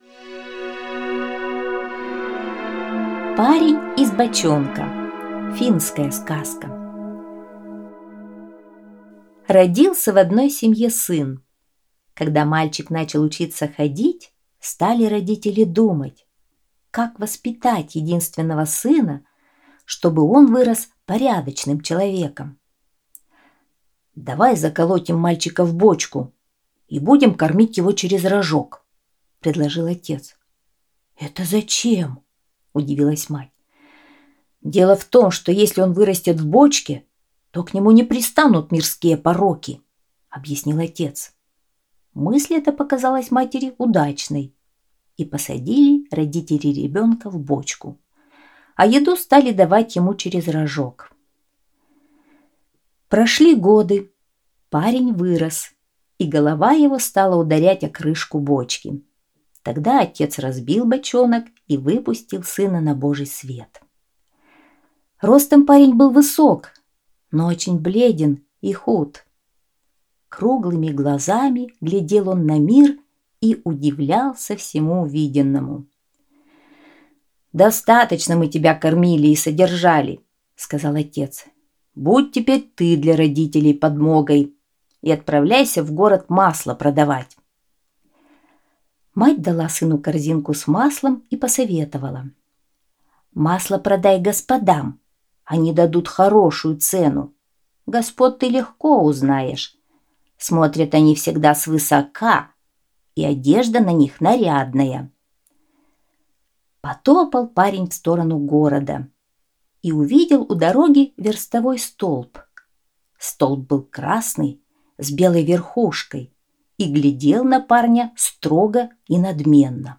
Аудиосказка «Парень из бочонка»